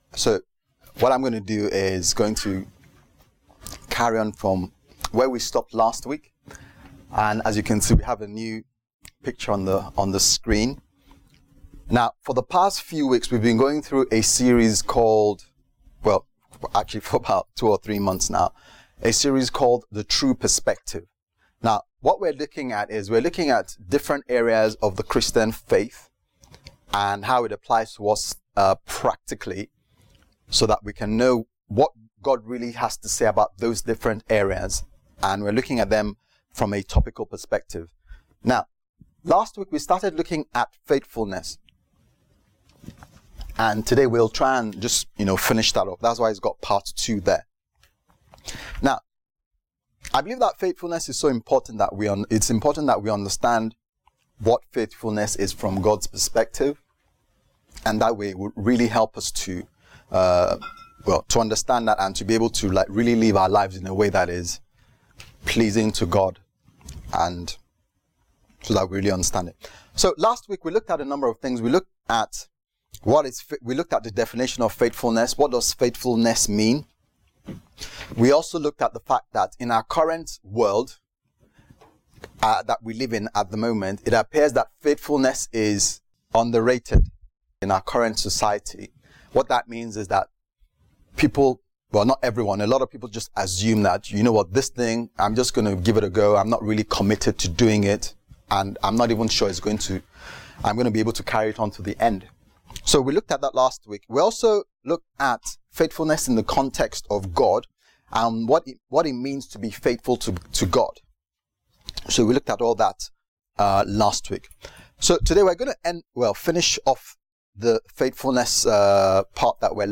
The True Perspective Service Type: Sunday Service « The True Perspective